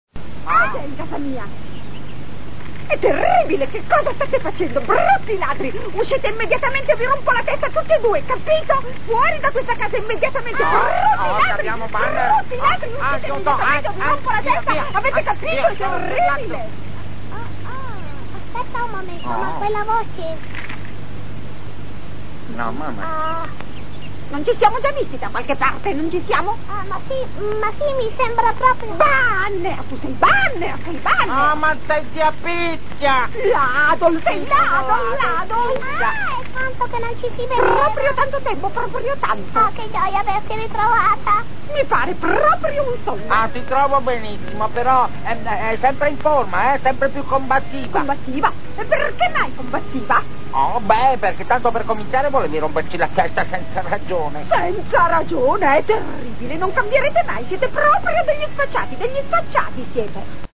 nel cartone animato "Lo scoiattolo Banner", in cui doppia Zia Picchia.